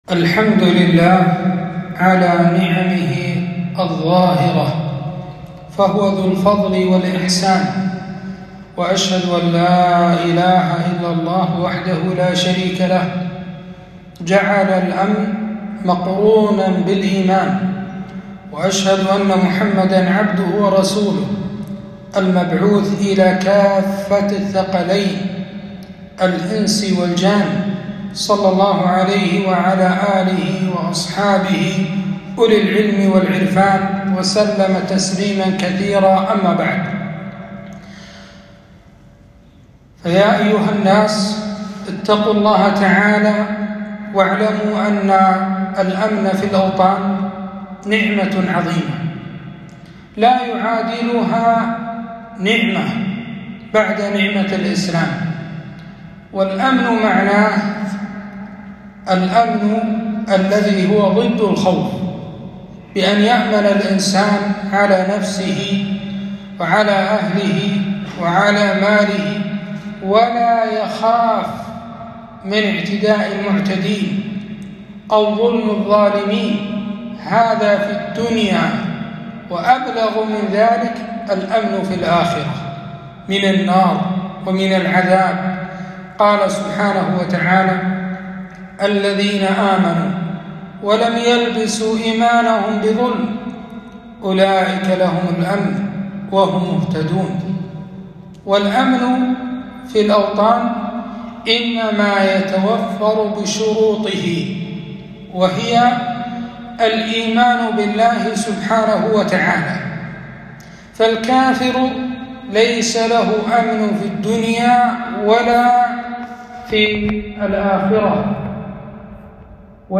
خطبة - نعمة الأمن والأمان